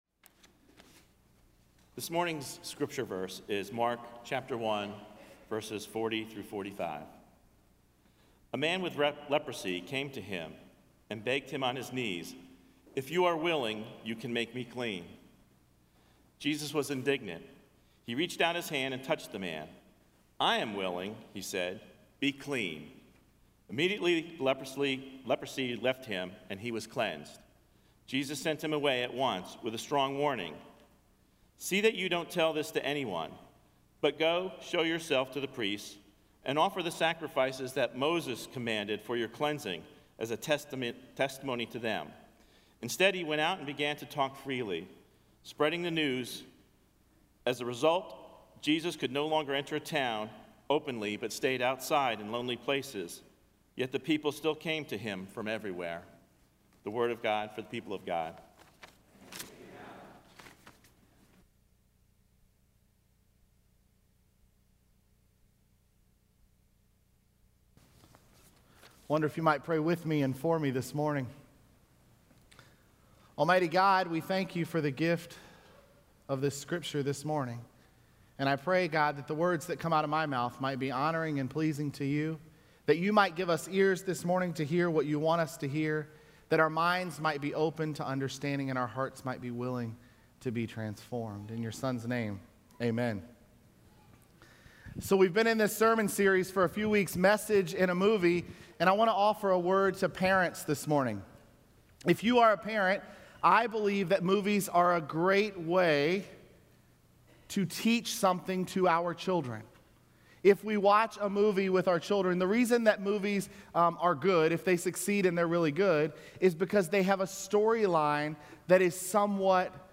sermon082414.mp3